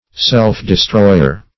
Self-destroyer \Self`-de*stroy"er\, n. One who destroys himself; a suicide.